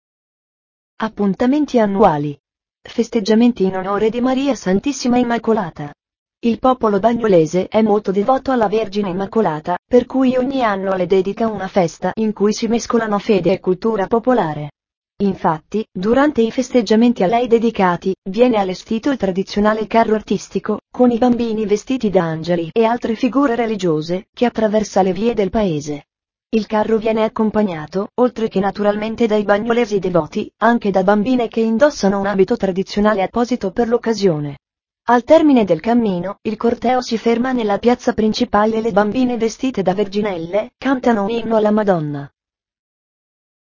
TRADIZIONALE CANTO DELLE VERGINELLE
Al termine del cammino, il corteo si ferma nella piazza principale e le bambine vestite da ” Verginelle“, cantano un inno alla Madonna.